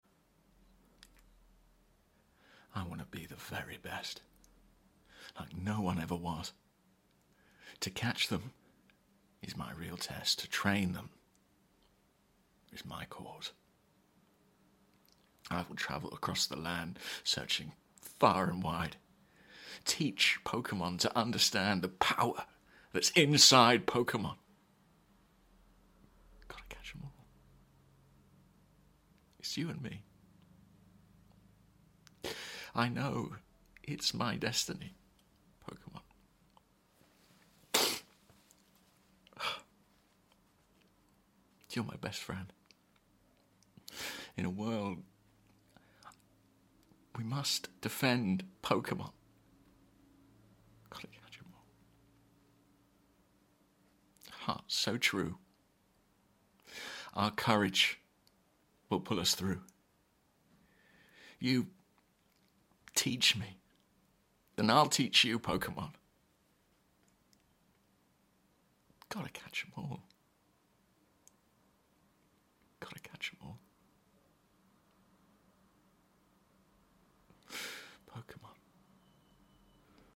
🎭 Dramatic Monologue: Pokémon (tv sound effects free download